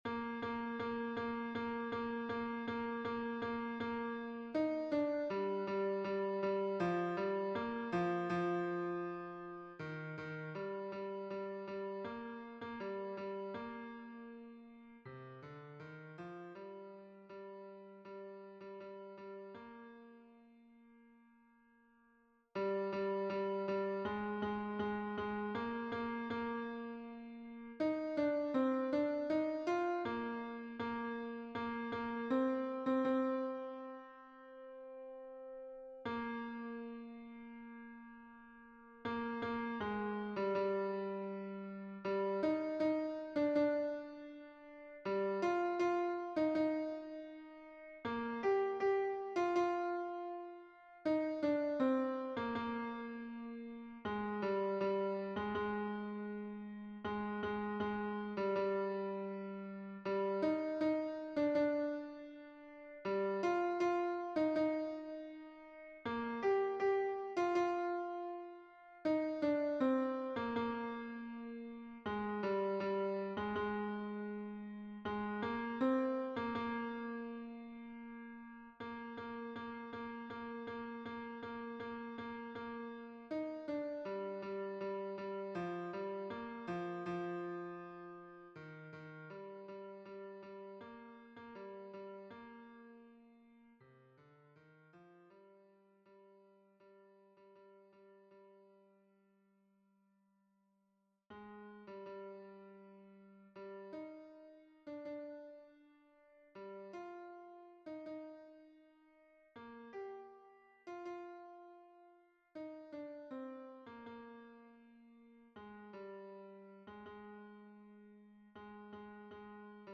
MP3 version piano
Tenor 1